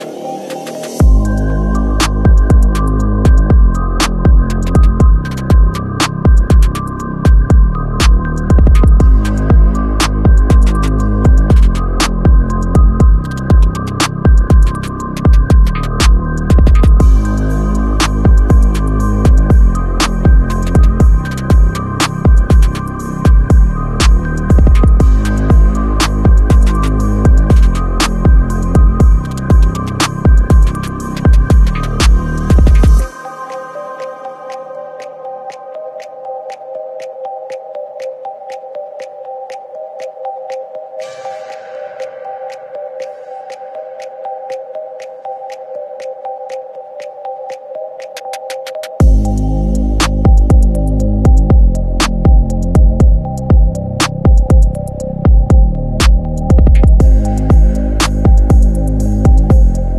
real sound frequencies sound effects free download